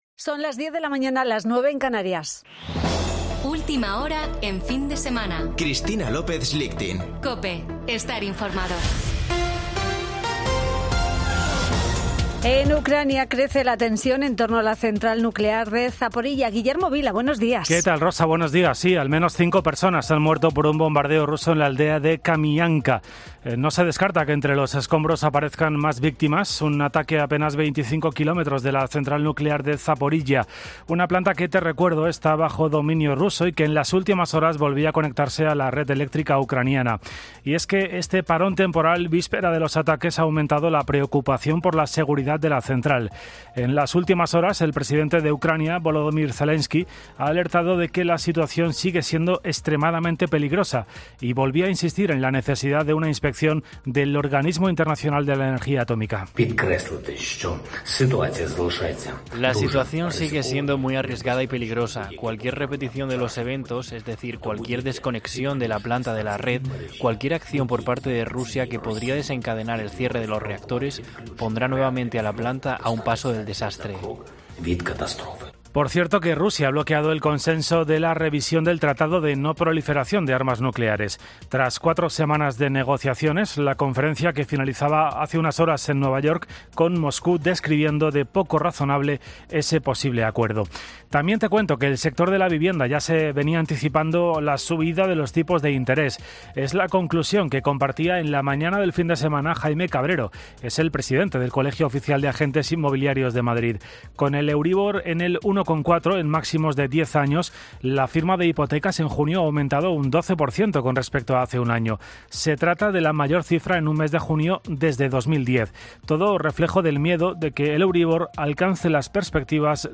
Boletín de noticias de COPE del 27 de agosto de 2022 a las 10.00 horas